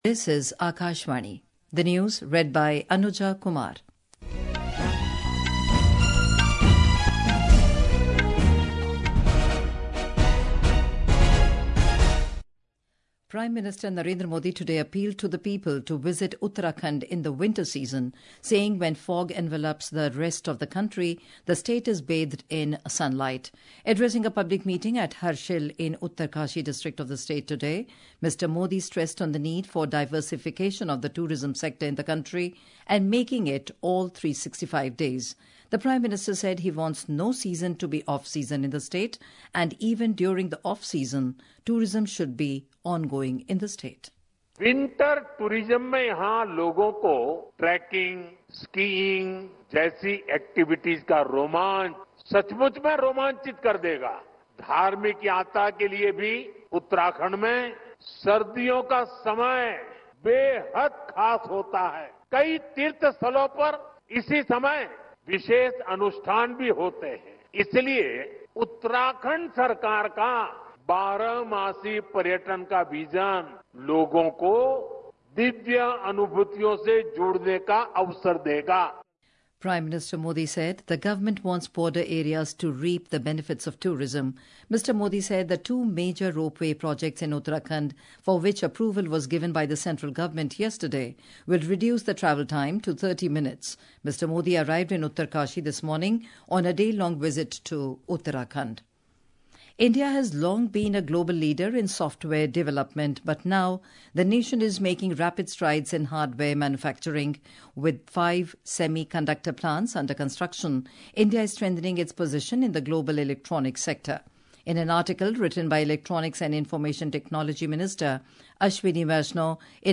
Hourly News | English